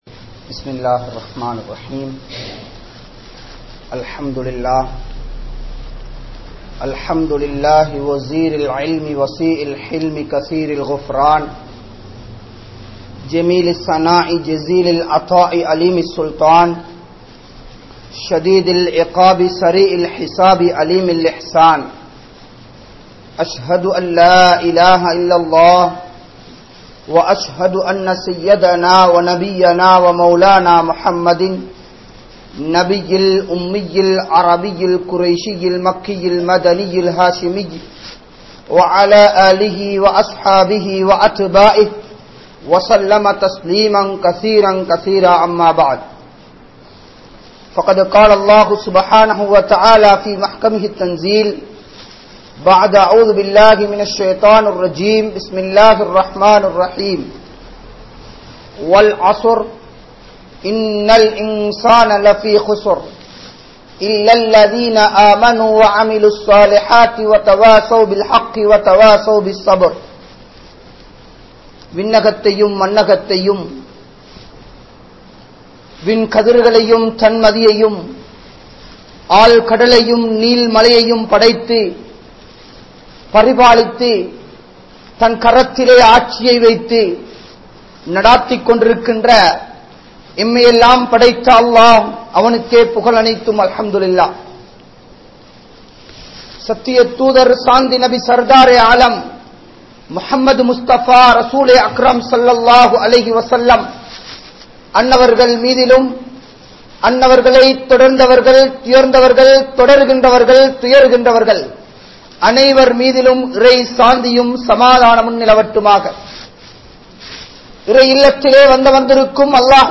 Muslimkale! Nithaanamaaha Seyatpadungal (முஸ்லிம்களே நிதானமாக செயற்படுங்கள்) | Audio Bayans | All Ceylon Muslim Youth Community | Addalaichenai